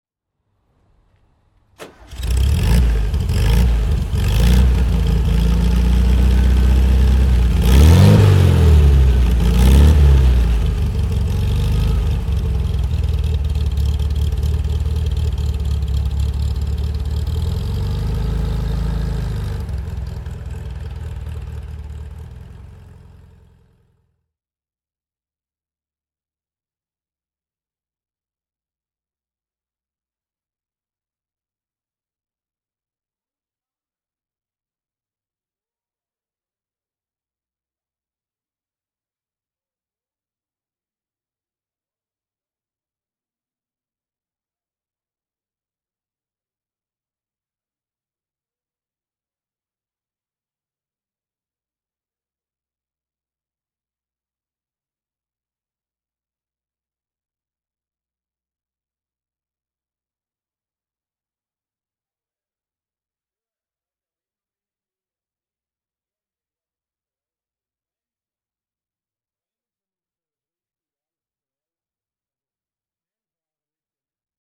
Sterling GT (1971) - Starten und Leerlauf